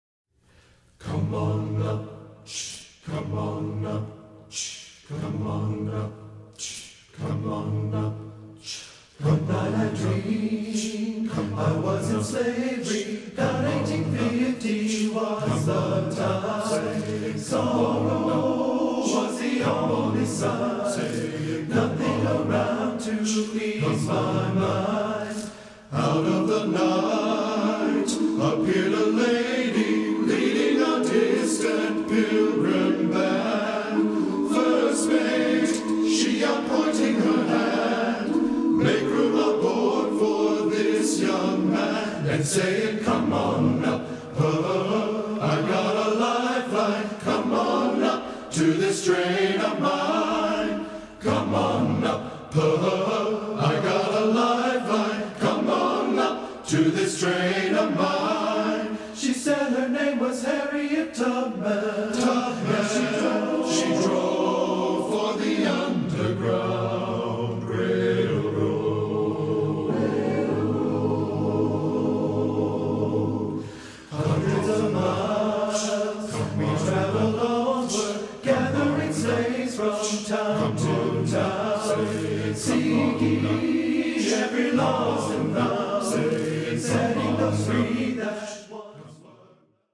TTBB A Cap
Choral Spiritual